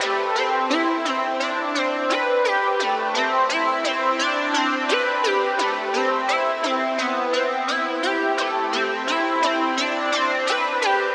Index of /DESN275/loops/Loop Set - Futurism - Synthwave Loops
BinaryHeaven_86_C_SynthRiff.wav